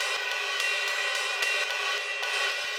RIDE_LOOP_9.wav